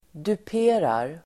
Uttal: [dup'e:rar]